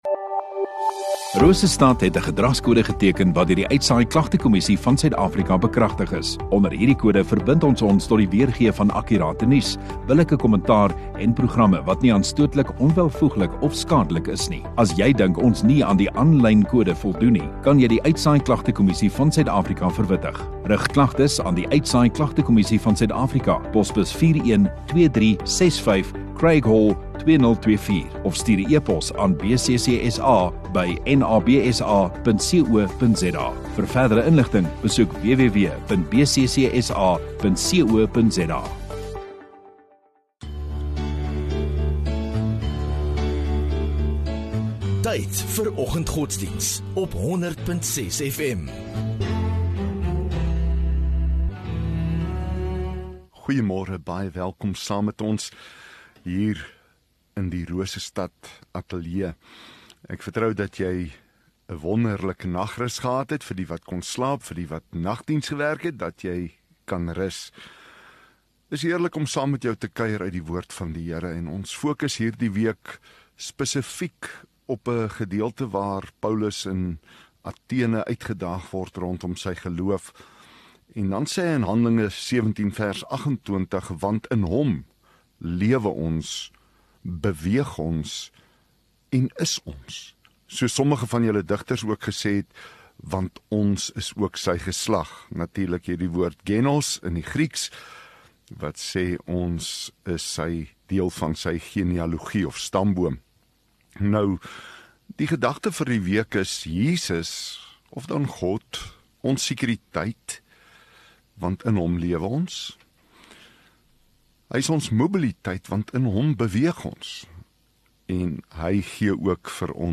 20 Aug Woensdag Oggenddiens